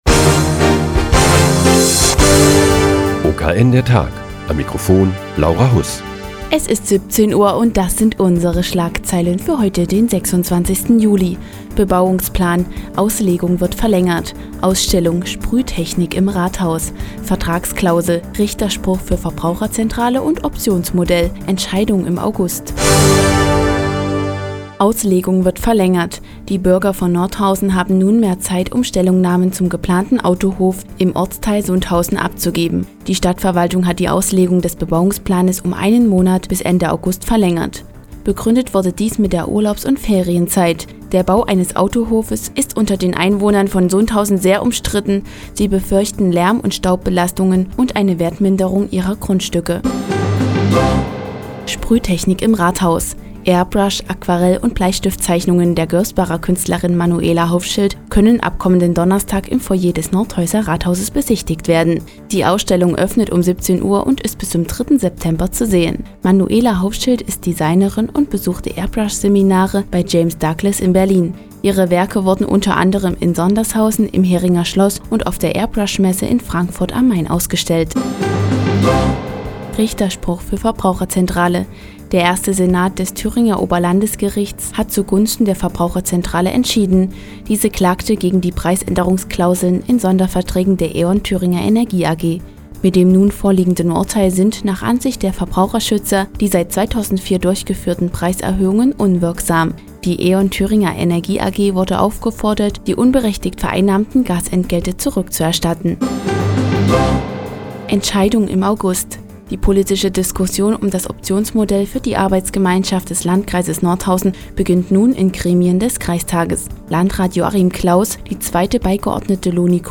Die tägliche Nachrichtensendung des OKN ist nun auch in der nnz zu hören. Heute geht es um die Auslegung des Bebauungsplans für den Autohof in Sundhausen und eine Airbrush- Ausstellung im Nordhäuser Rathaus.